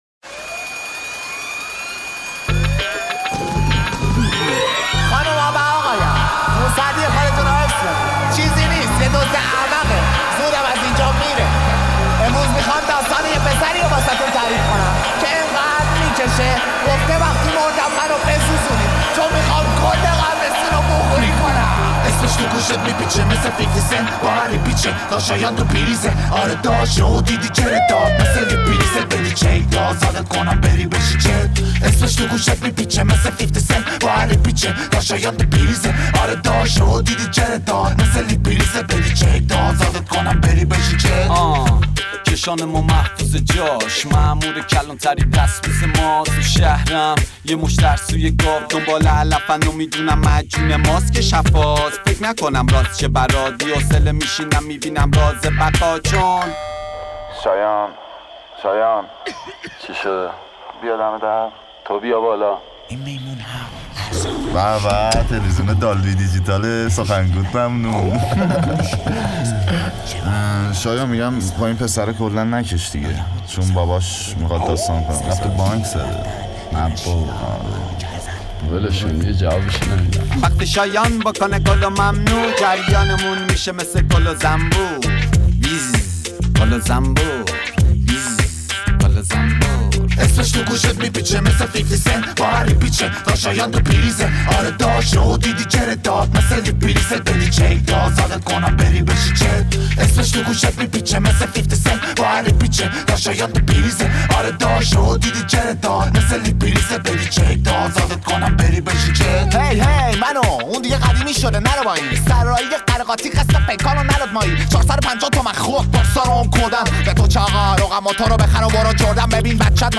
رپ
از برترین رپر های فارسی